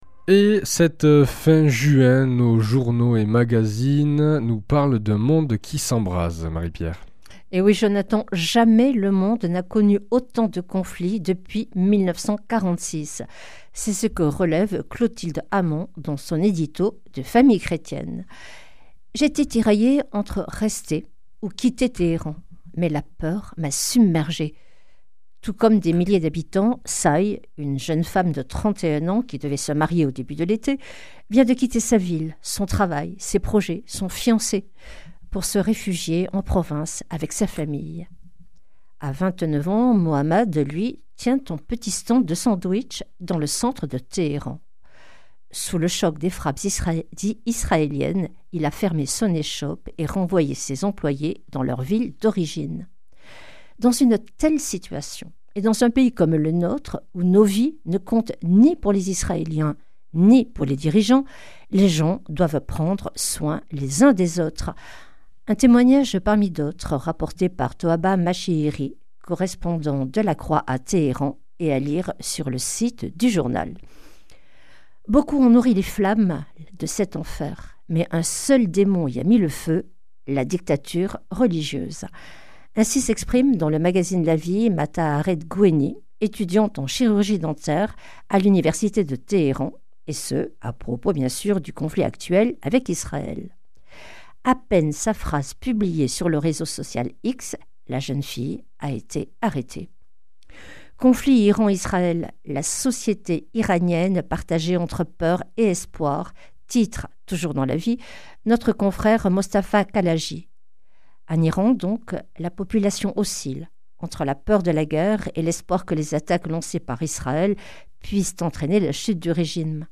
vendredi 20 juin 2025 La revue de presse chrétienne Durée 5 min
Une émission présentée par